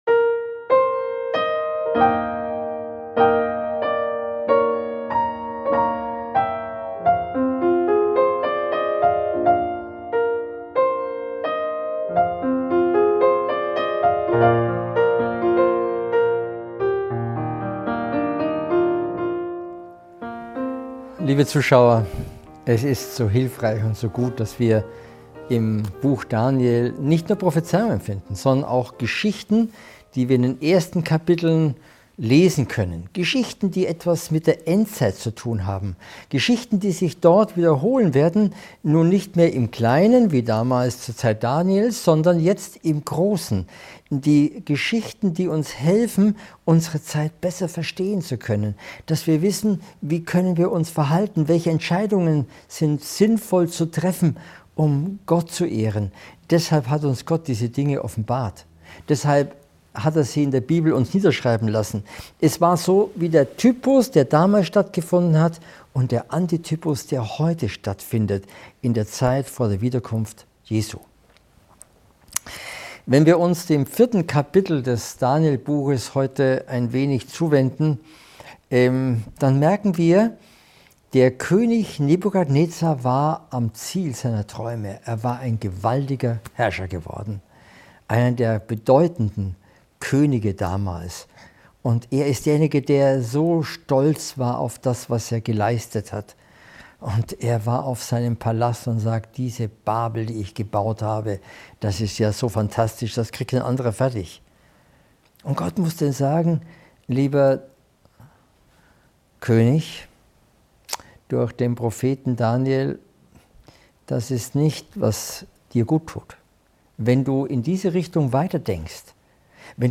In diesem fesselnden Vortrag über die Geschichten im Buch Daniel, insbesondere die des Königs Nebukadnezar, wird gezeigt, wie Gott durch demütigende Erfahrungen Führung gibt.